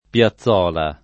vai all'elenco alfabetico delle voci ingrandisci il carattere 100% rimpicciolisci il carattere stampa invia tramite posta elettronica codividi su Facebook piazzola [ p L a ZZ0 la ] o piazzuola [ p L a ZZU0 la ] s. f. — sim. i top.